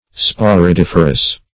Search Result for " sporidiferous" : The Collaborative International Dictionary of English v.0.48: Sporidiferous \Spo`ri*dif"er*ous\ (sp[=o]`r[i^]*d[i^]f"[~e]r*[u^]s), a. [Sporidium + -ferous.]